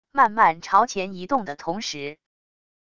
慢慢朝前移动的同时wav音频生成系统WAV Audio Player